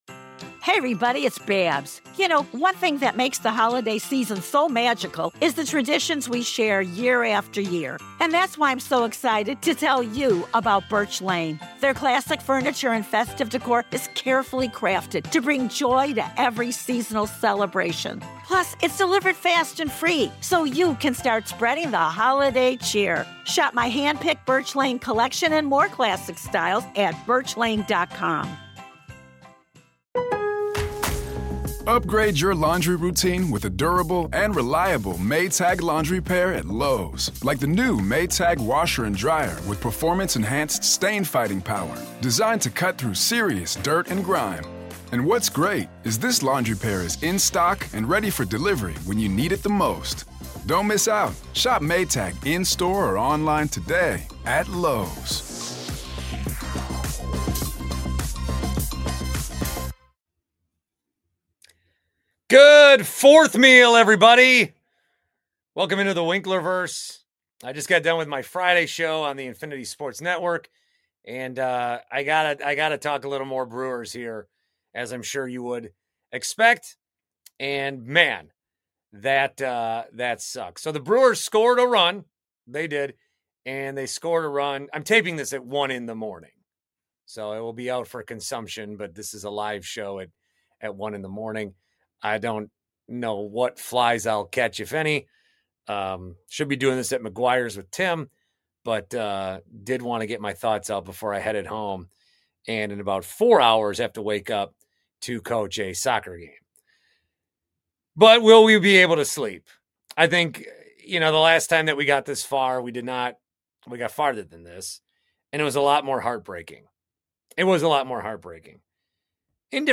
A late night 1am live stream to break it all down Hosted by Simplecast, an AdsWizz company.